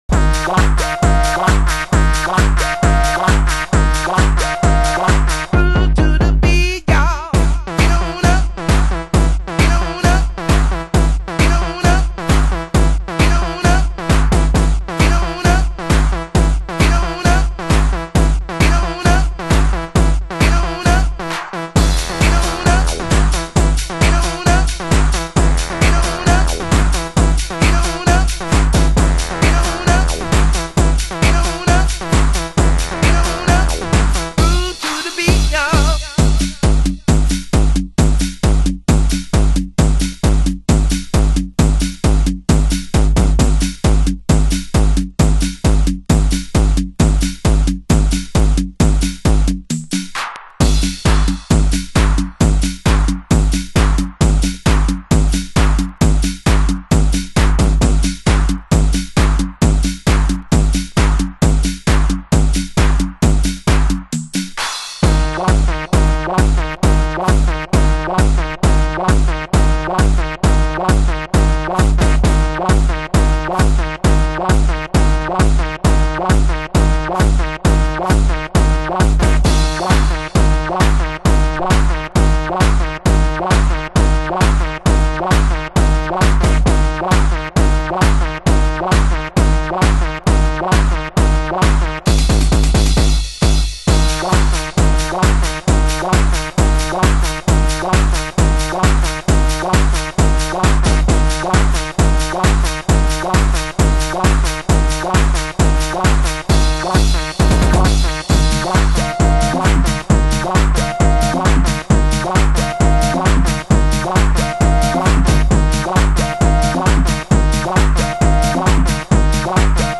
HOUSE MUSIC
盤質：少しチリパチノイズ有